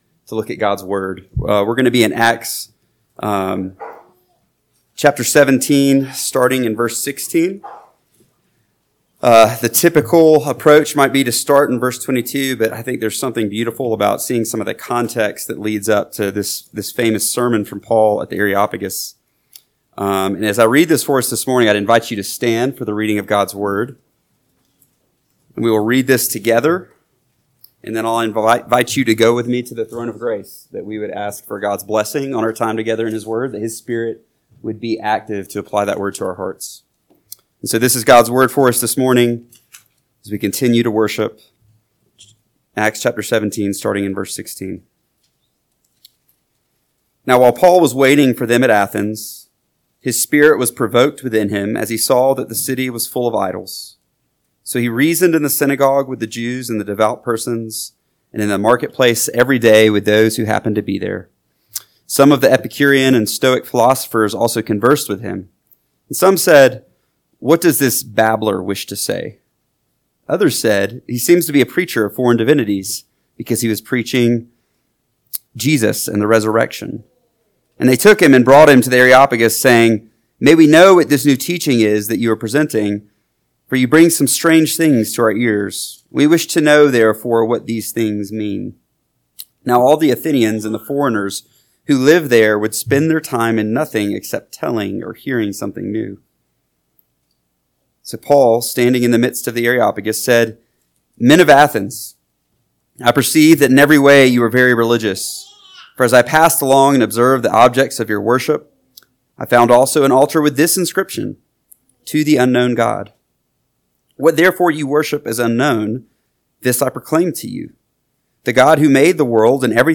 AM Sermon – 7/13/2025 – Acts 17:16-33 – Northwoods Sermons